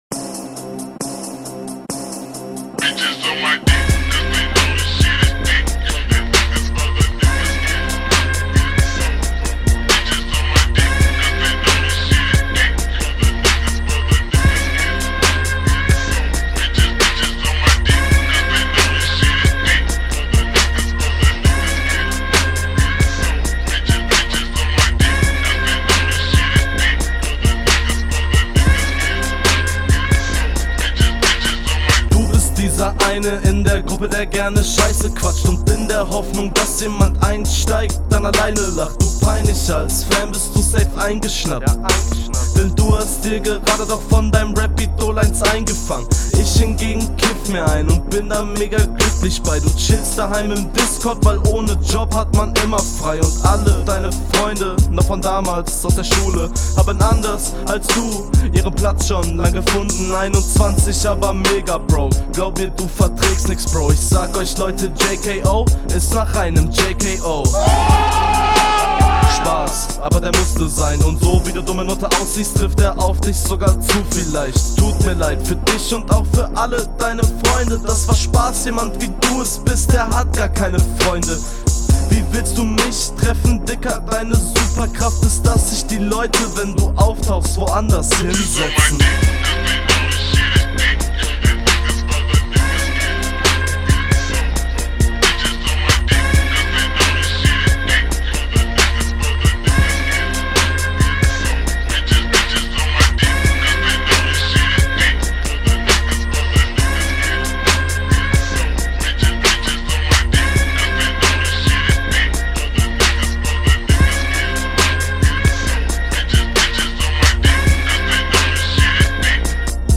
Flow: Flowlich nichts außerordentlich starkes aber ganz solide.
Flow:flow sehr sicher und souverän.